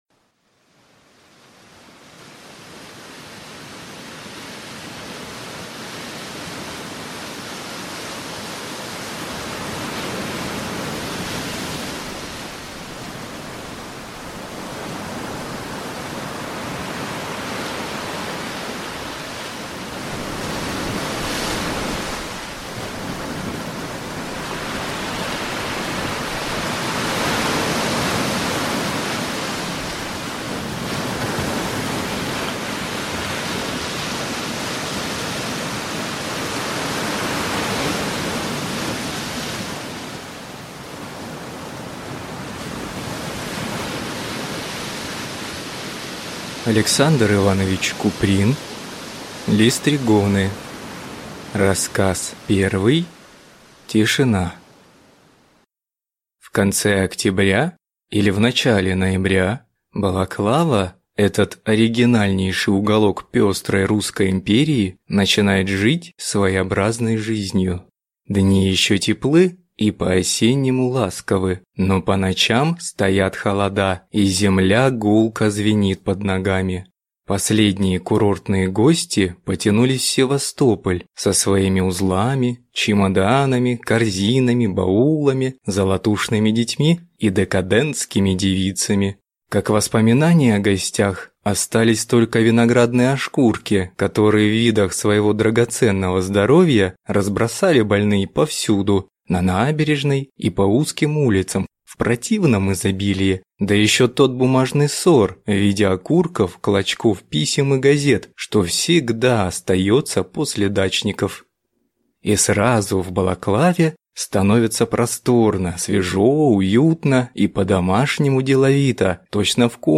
Аудиокнига Листригоны | Библиотека аудиокниг